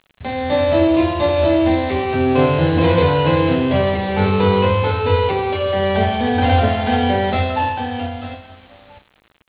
ポリフォニーとは一つ一つが独立したた多声からなる音楽。
ポリフォニー例 J.S.Bach：インベンションBWV772-1から